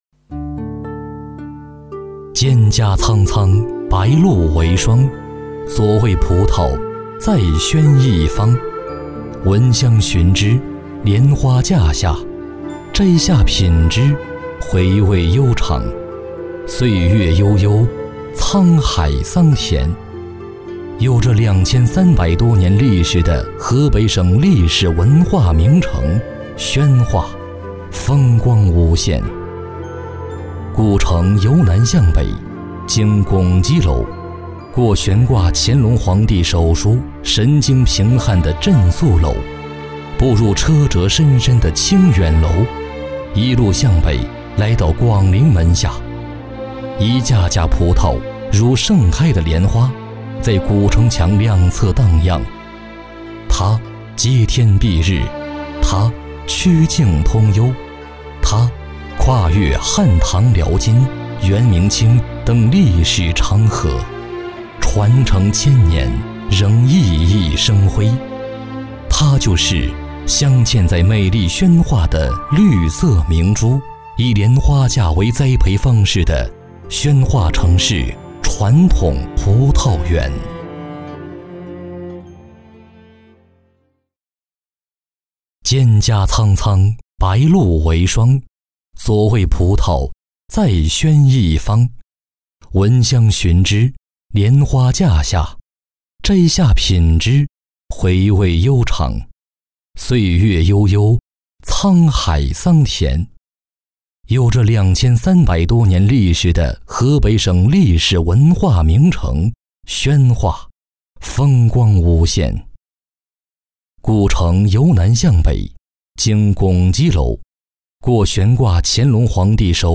国语中年素人 、男微电影旁白/内心独白 、宣传片 、60元/分钟男2 国语 男声 走心旁白-宣传片-天津九河香舍 素人